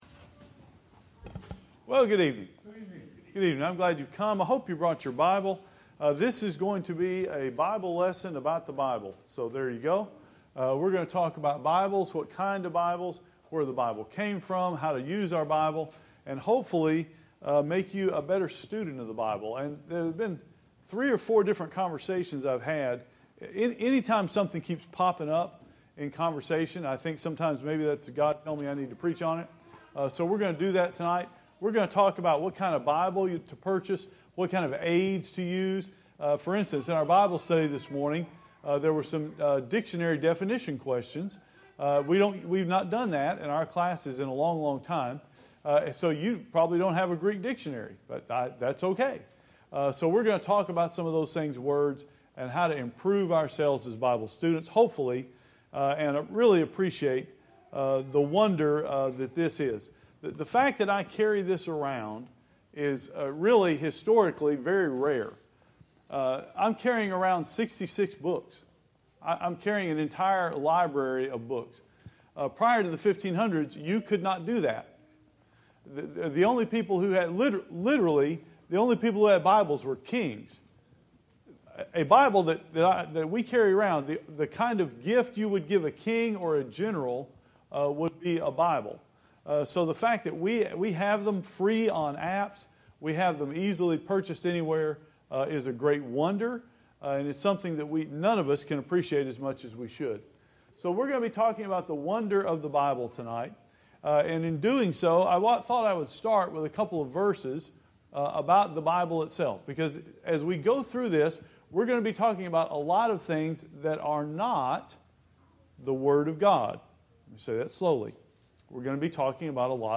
Here are some recommendations for Bible Study tools mentioned in this sermon.